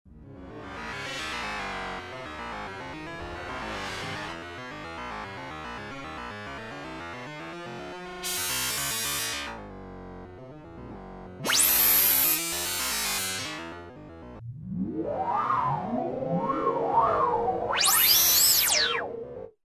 16 Bit Digital Synthesizer
demo FX: 1 2 3 4